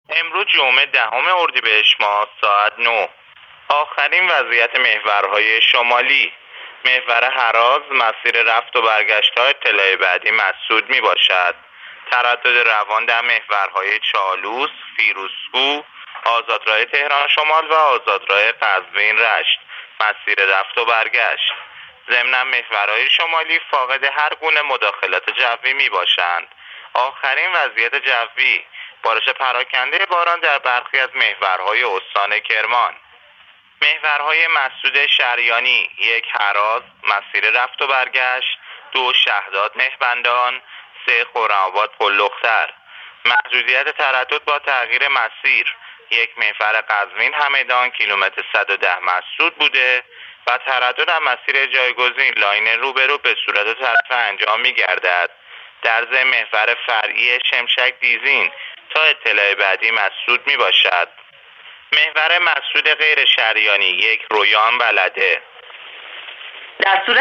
گزارش رادیو اینترنتی از آخرین وضعیت ترافیکی جاده‌ها تا ساعت ۹ دهم اردیبهشت